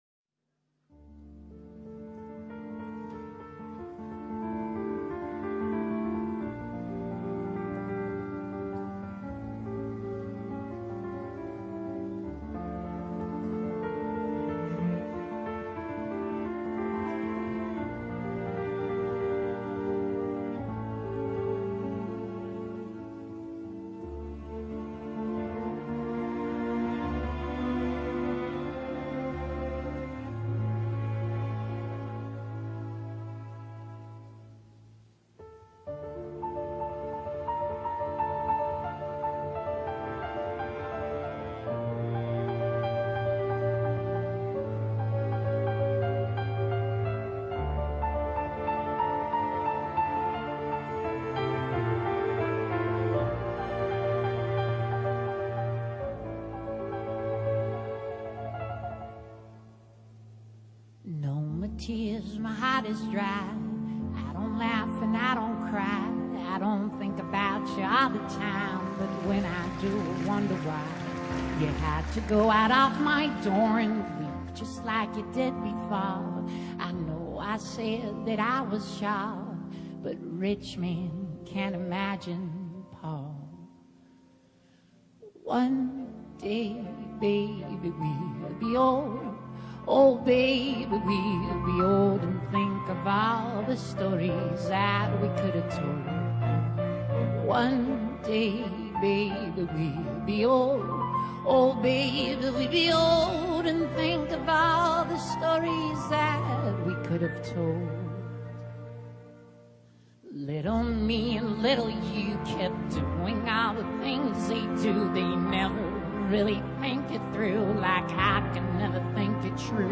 😮 ) ed intensa interpretazione della canzone